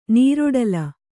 ♪ nīroḍala